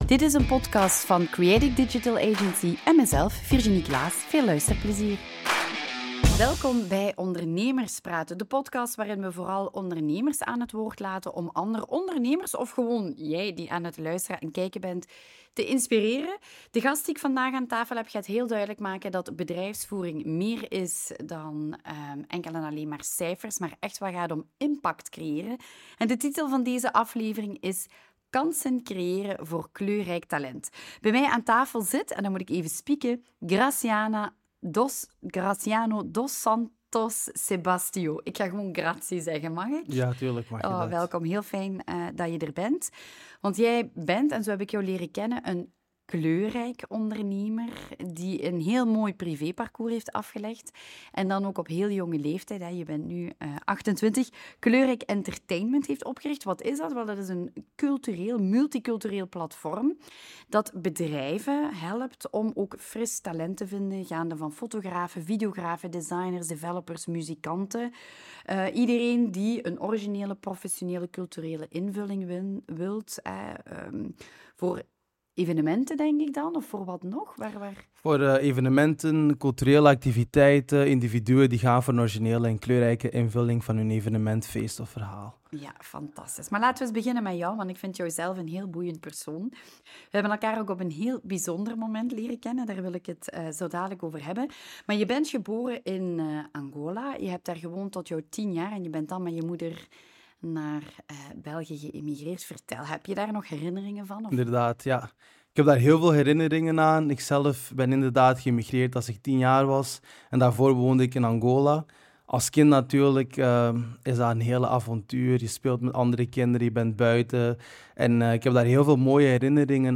Audio narration of: Creating Opportunities for Diverse Talent in Business